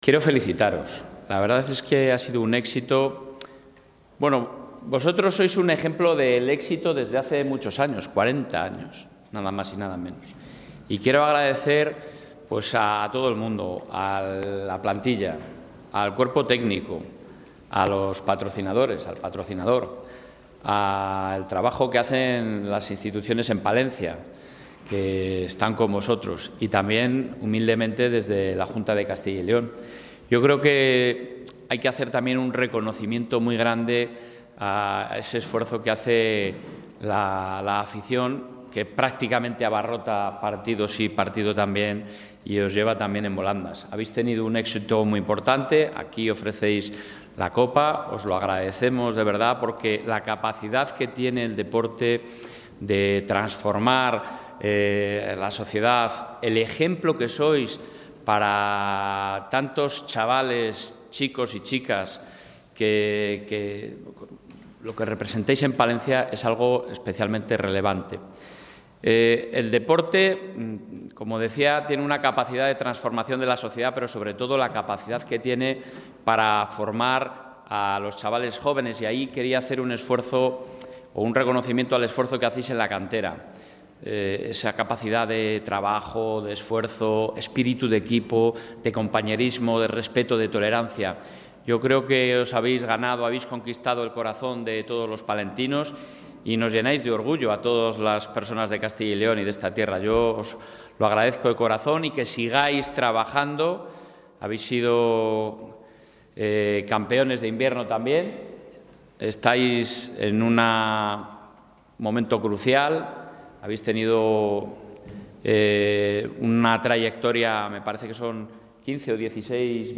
En la recepción celebrada en la Sede de la Presidencia de la Junta de Castilla y León, el presidente del Gobierno autonómico,...
Intervención del presidente.